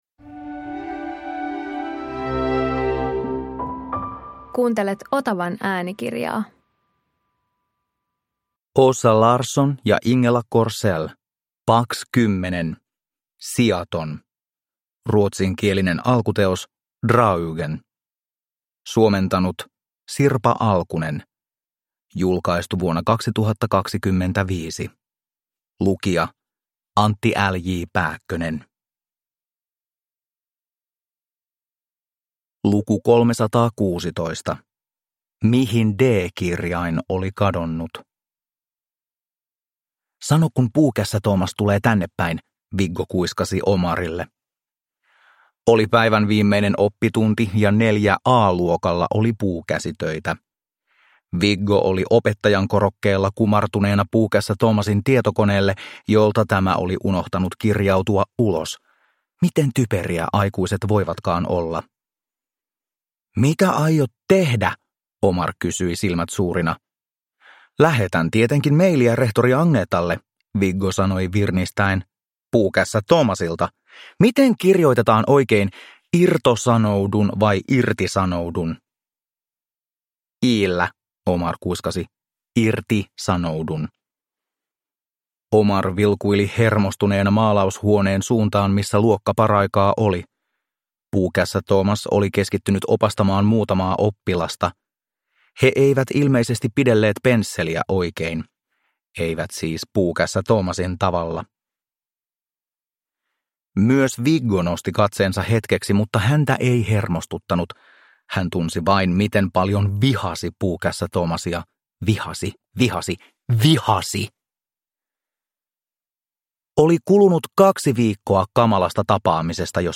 Pax 10 - Sijaton (ljudbok) av Åsa Larsson | Bokon